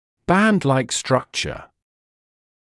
[‘bændlaɪk ‘strʌkʧə][‘бэндлайк ‘стракчэ]структура, похожая на полоску